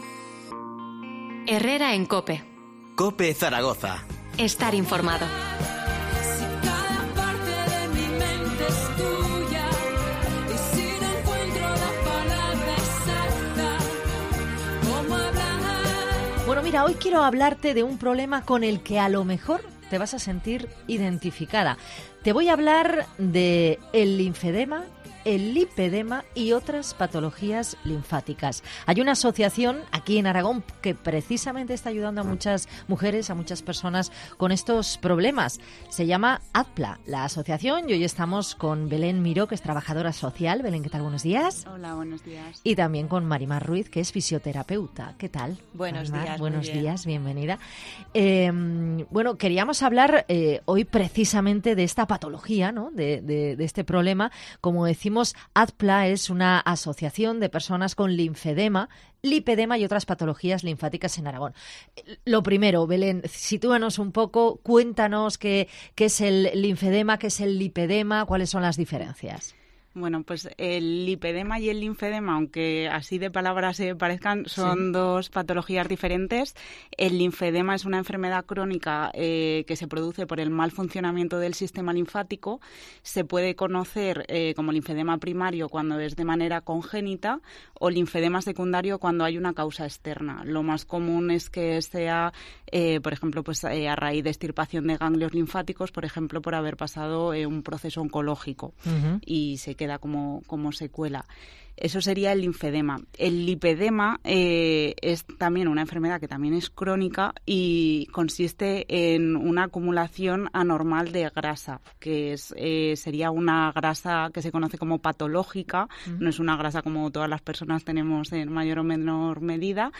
Entrevista Asociación ADPLA (Linfedema, lipedema)